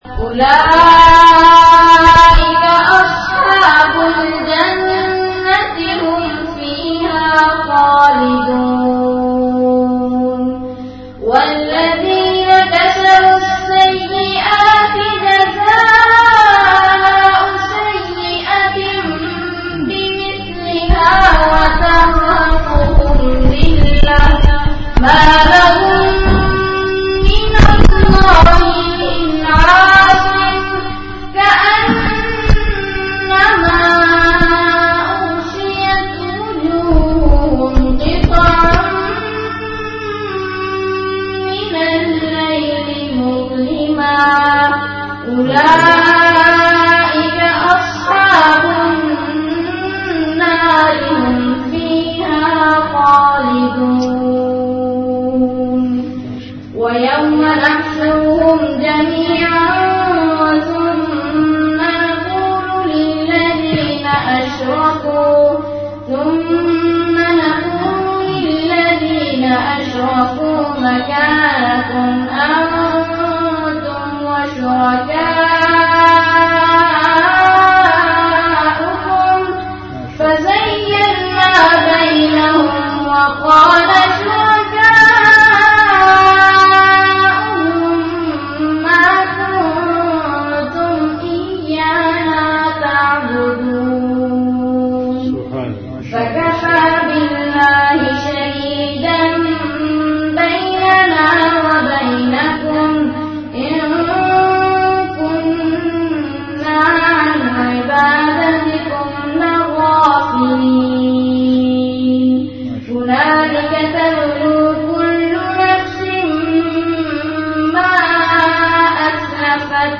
Majlis-e-Zikr
After Isha Prayer